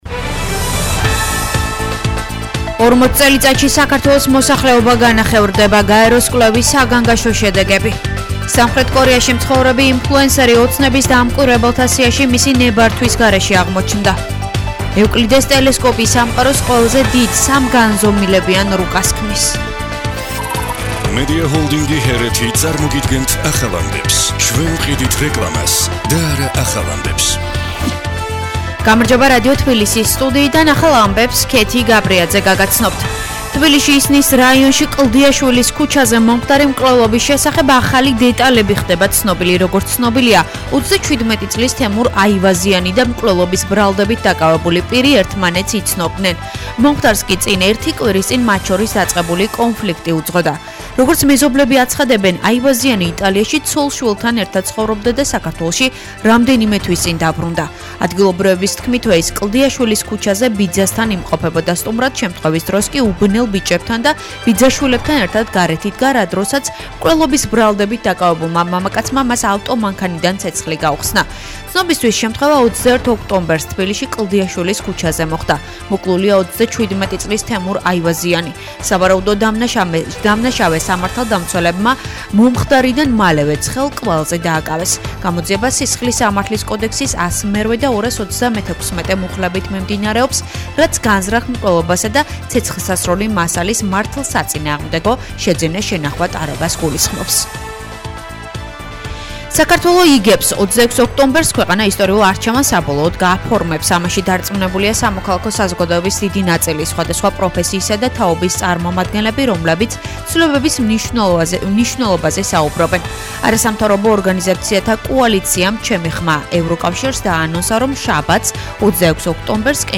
ახალი ამბები 19:00 საათზე – HeretiFM